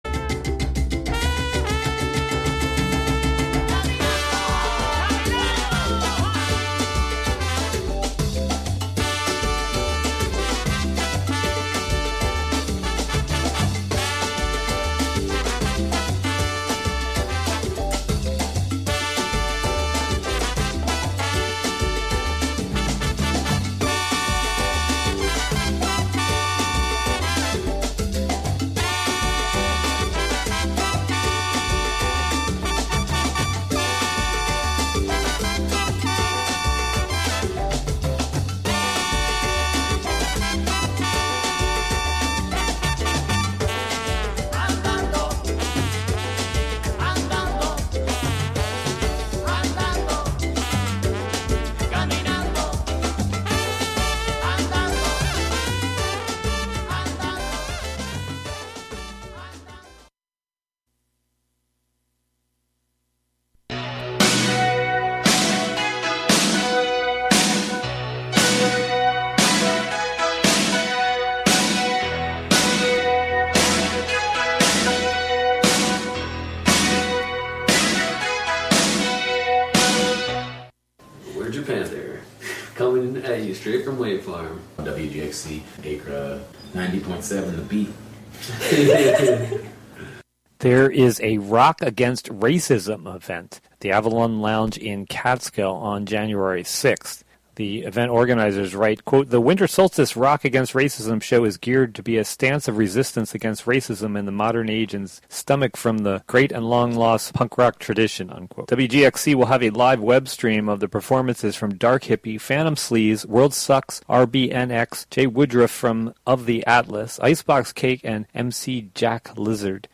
indigenous folk-psychedelia
electronica funk
alt-country